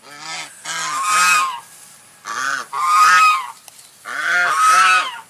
Angsa_Suara.ogg